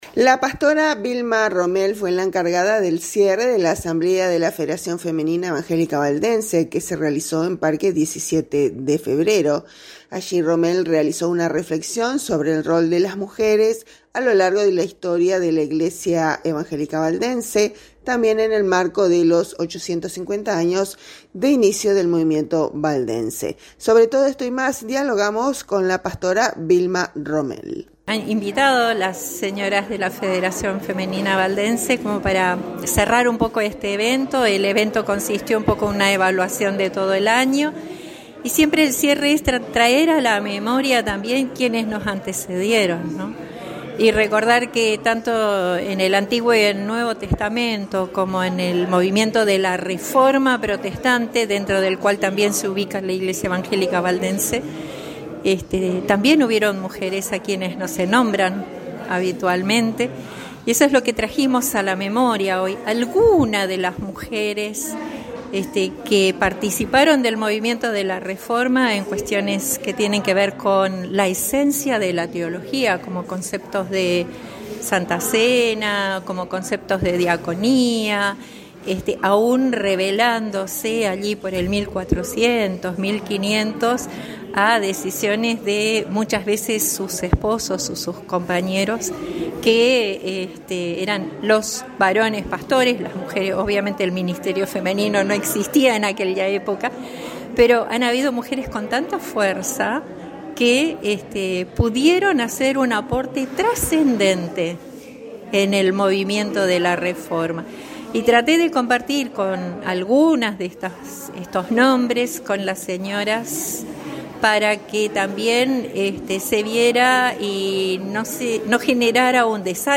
Sobre todo esto y más, dialogamos con la pastora